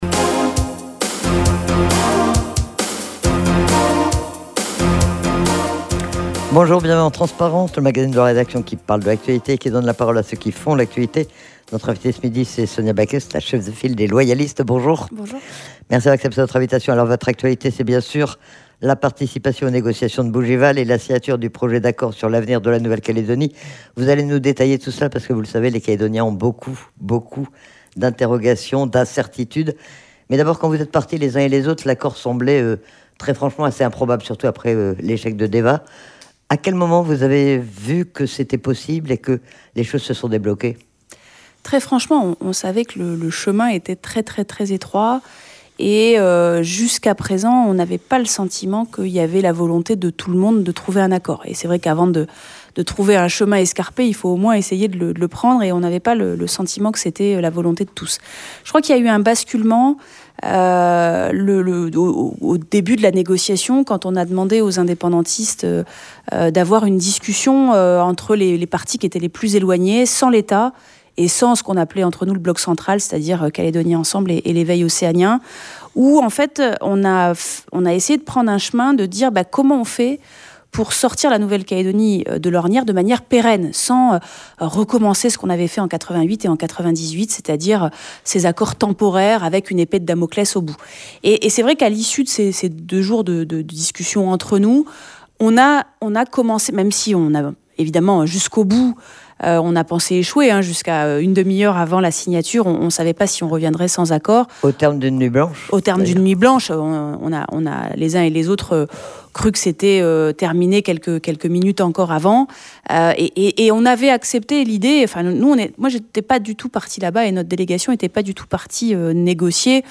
Que contient vraiment le projet d'accord sur l'avenir de la Nouvelle-Calédonie signé à Bougival ? Sonia Backès, la chef de file des Loyalistes, est interrogée sur le déroulement des négociations, sur le contenu de ce projet d'accord, sur les concessions faites de part et d'autre et sur le calendrier de mise en œuvre.